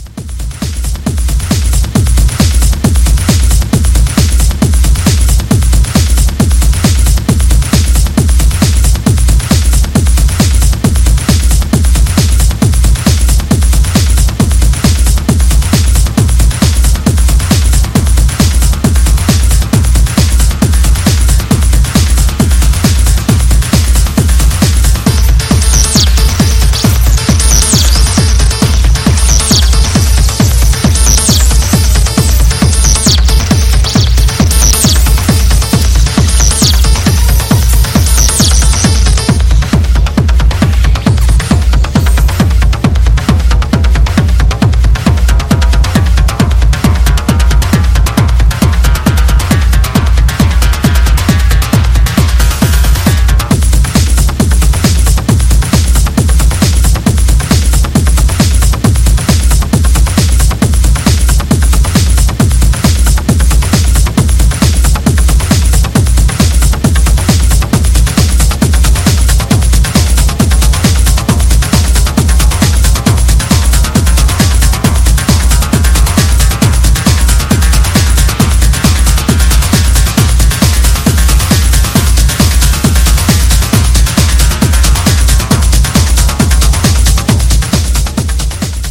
Techno 12 Inch Ep